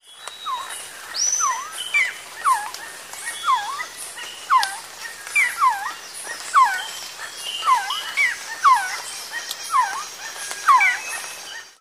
La piramide Maya di Kukulkan a Chichen Itza (costruita tra il 900 e il 550 A.C) produce uno strano echo: qualcuno dice che se si battono le mani di fronte alla scalinata principale, si sente in risposta il verso dell'uccello sacro dei Maya, il Quetzal.
Quetzal.wav